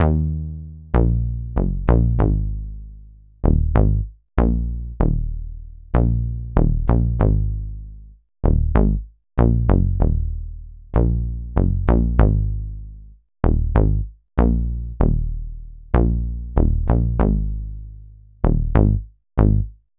Night Rider - Rubber Bass.wav